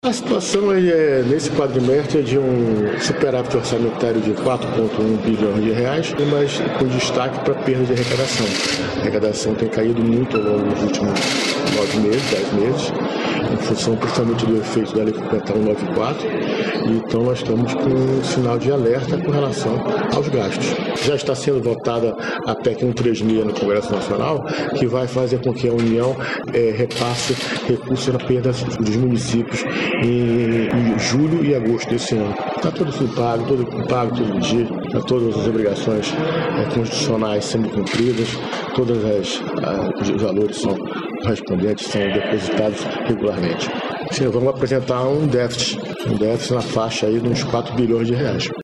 Sonora do secretário da Fazenda, Renê Garcia Junior, sobre a apresentação do Relatório de Gestão Fiscal referente ao segundo quadrimestre de 2023